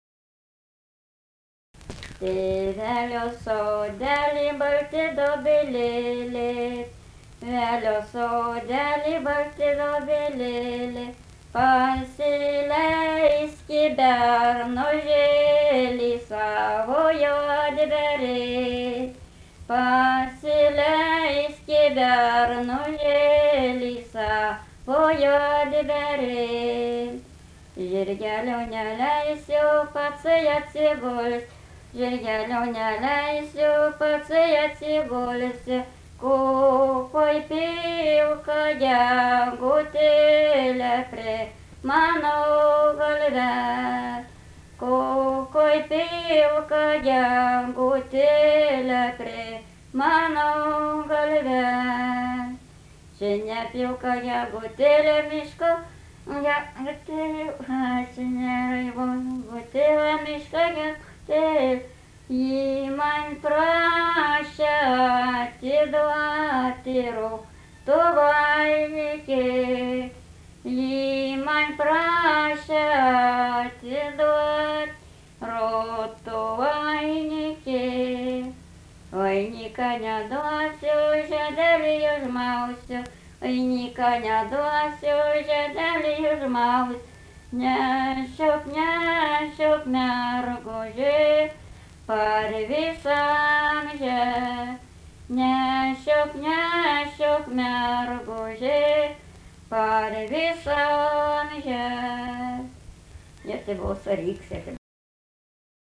daina
Meilūnai
vokalinis